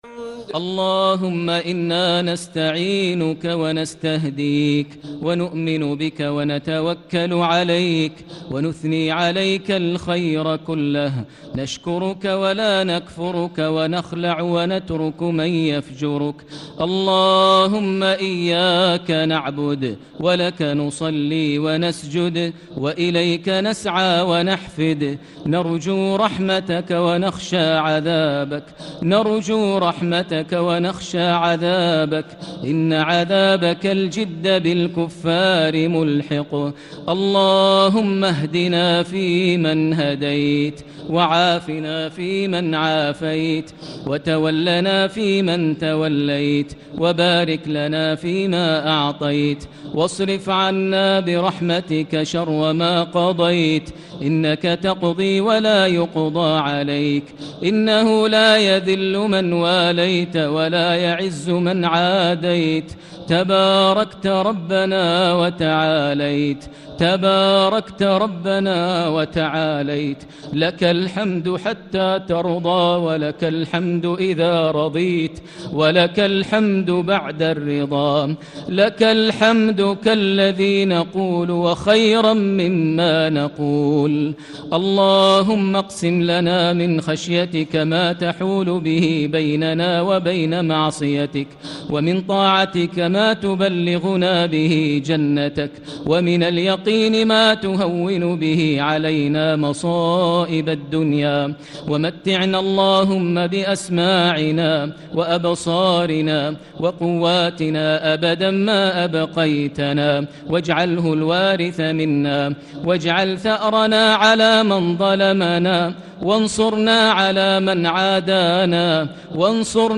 دعاء القنوت ليلة 22 رمضان 1440هـ | Dua for the night of 22 Ramadan 1440H > تراويح الحرم المكي عام 1440 🕋 > التراويح - تلاوات الحرمين